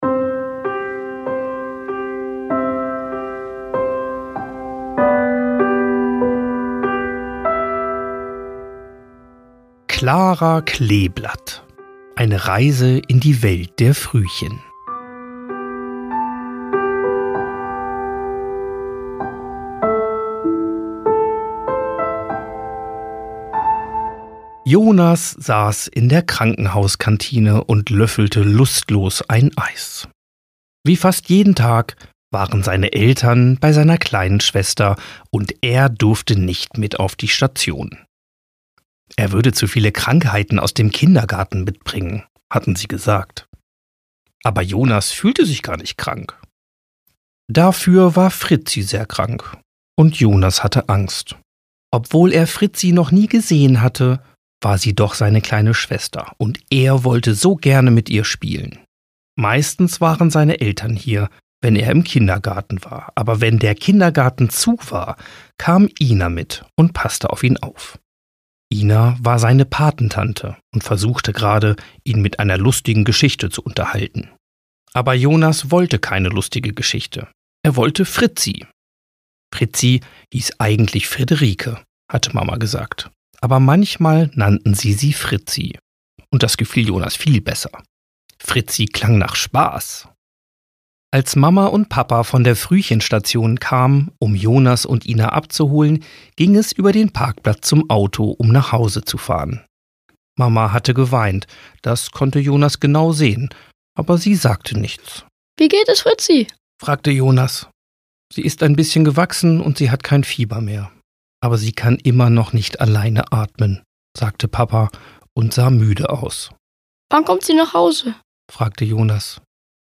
„Klara Kleeblatt“ ist eine tröstliche, fantasievolle Geschichte über Angst und Hoffnung, über Geschwisterliebe und darüber, wie Kinder schwierige Situationen verstehen lernen können. Ein Hörbuch, das nicht nur erklärt, sondern stärkt – für Kinder, Eltern und alle, die Frühchen auf ihrem Weg begleiten.